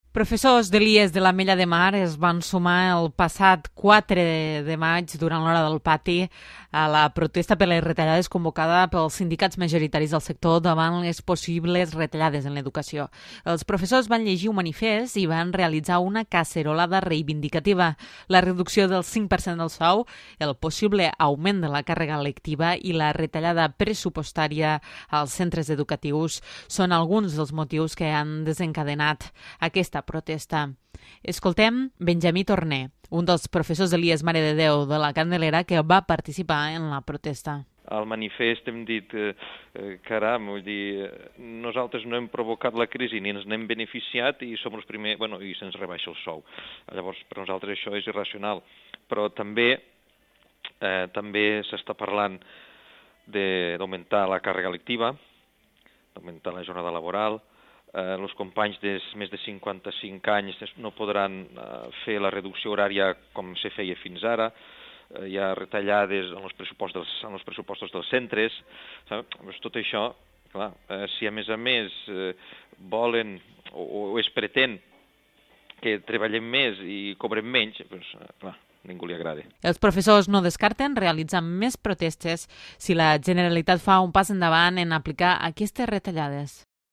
Els professors vanllegir un manifest i van realitzar una casserolada reivindicativa.